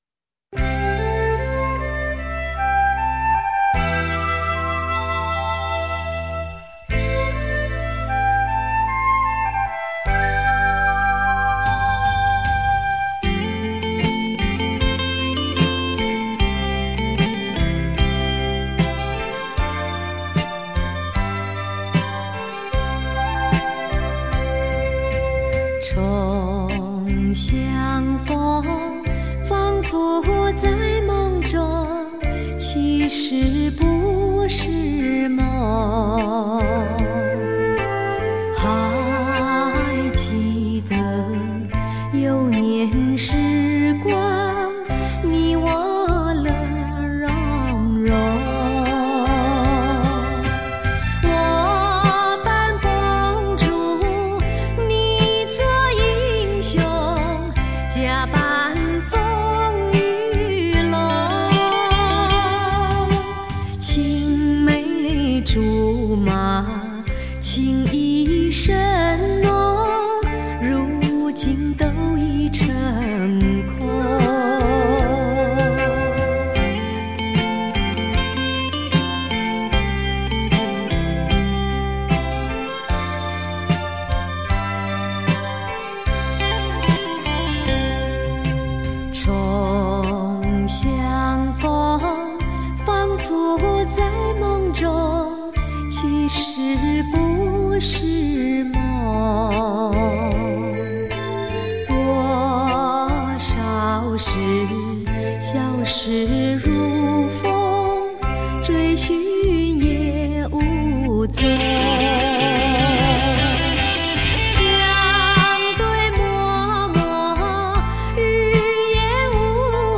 舞厅规格
勃鲁斯歌唱版
将自己投入感性的歌声中